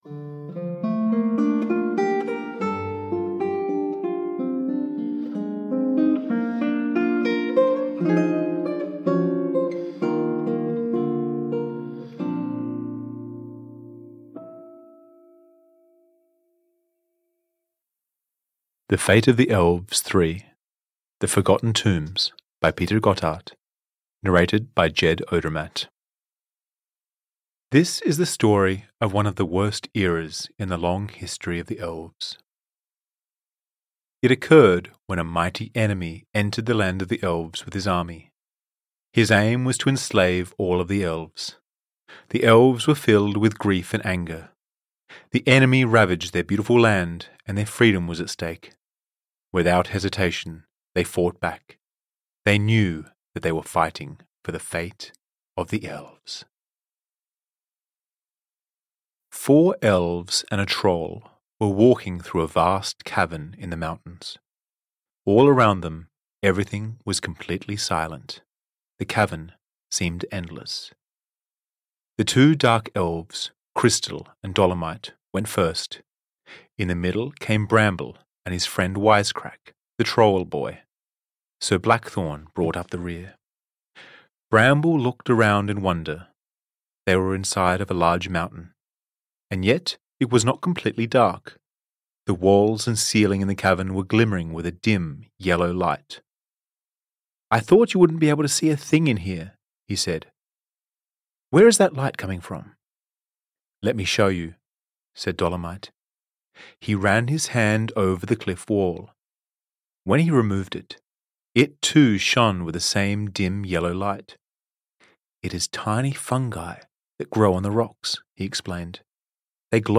Audio knihaThe Fate of the Elves 3: The Forgotten Tombs (EN)
Ukázka z knihy